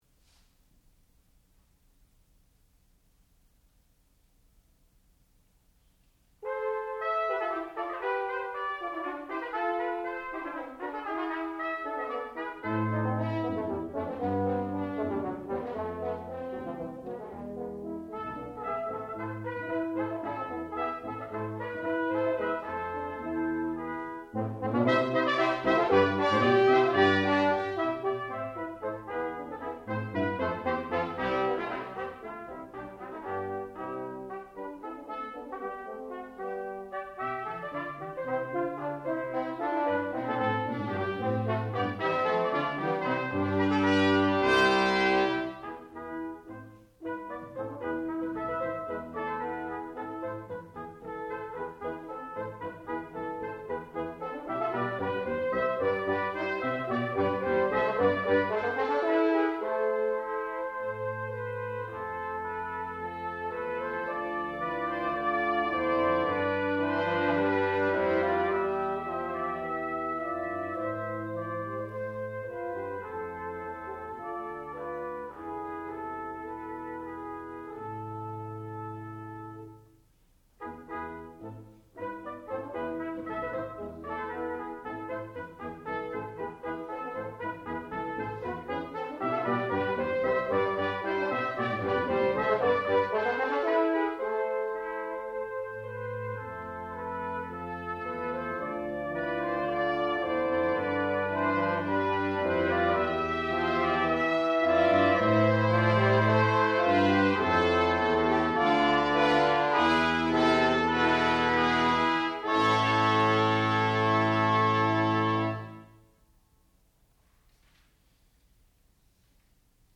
sound recording-musical
classical music
The Shepherd Brass Quintet (performer).